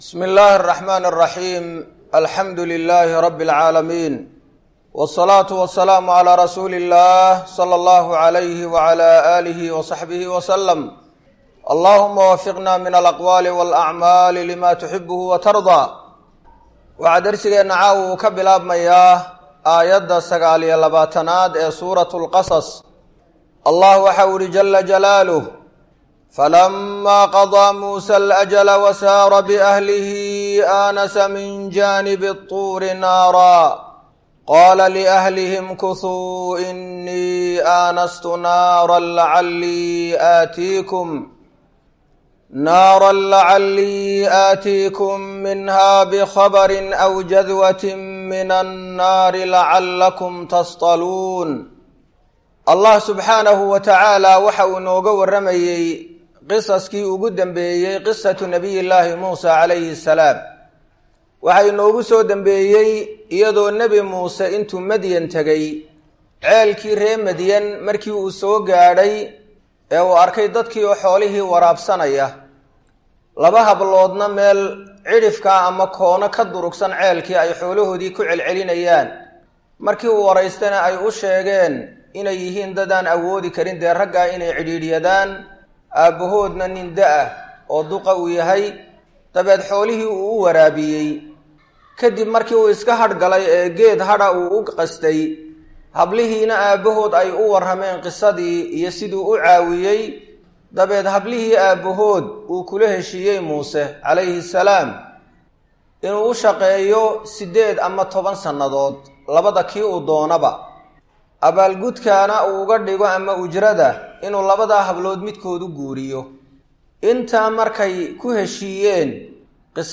Waa Tafsiirka Qur’aanka Ee Ka Socda Masjid Ar-Rashiid – Hargaisa